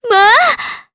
Juno-Vox_Happy4_jp.wav